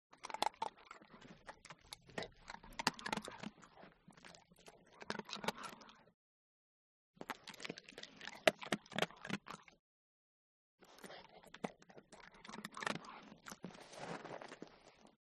دانلود آهنگ غذا خوردن سگ از افکت صوتی انسان و موجودات زنده
دانلود صدای غذا خوردن سگ از ساعد نیوز با لینک مستقیم و کیفیت بالا
جلوه های صوتی